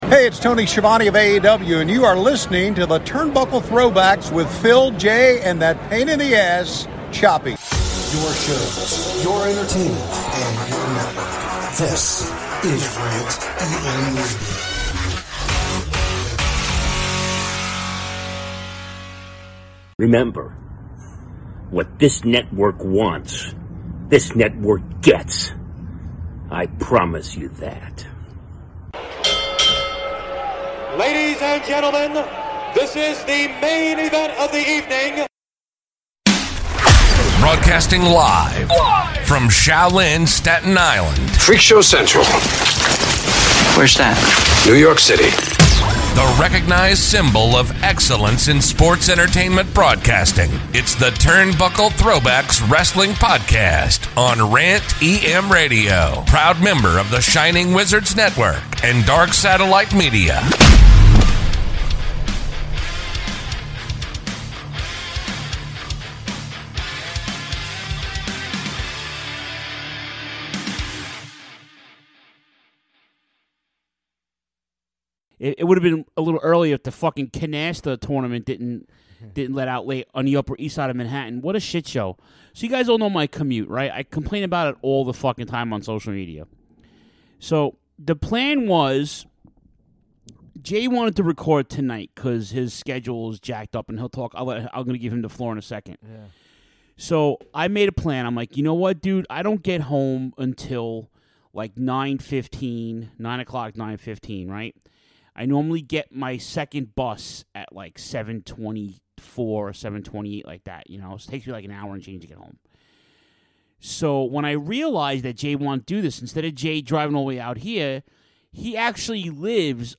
back in studio